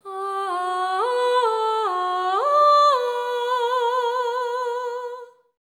ETHEREAL12-L.wav